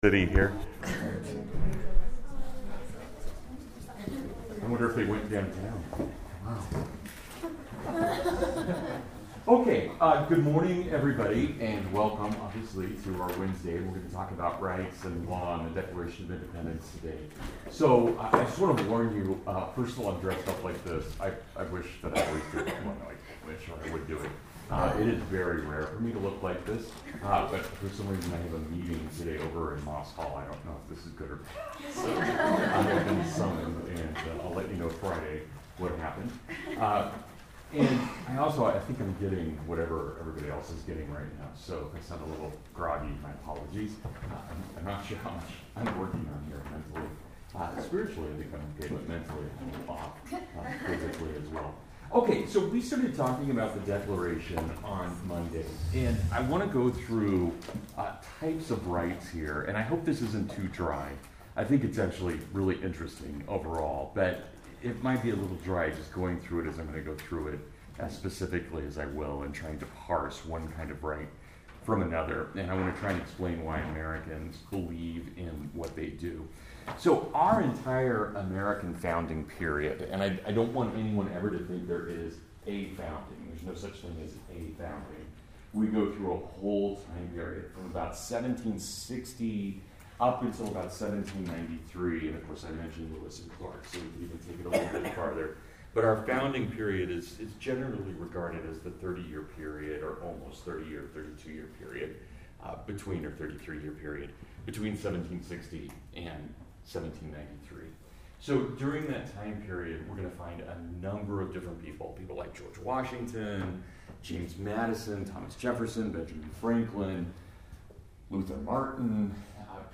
What is the American Republic (Full Lecture)
The audio quality is pretty weak, and there are a few minutes of noise and confusion at the beginning.